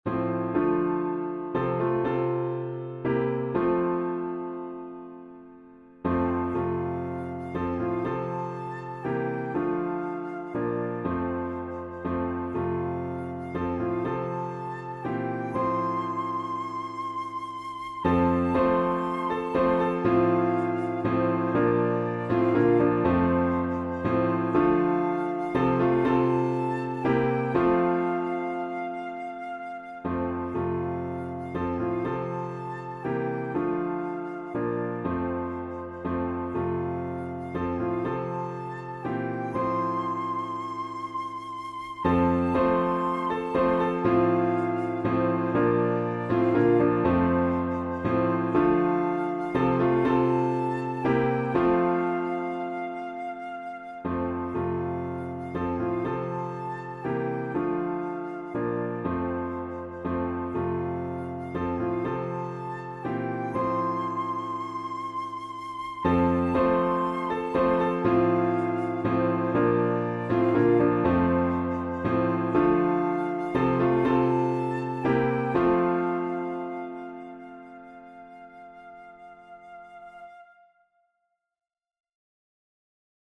beautiful and expressive beginner flute solo
• Piano accompaniment (PDF)
• Easy flute solo with expressive phrasing